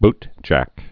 (btjăk)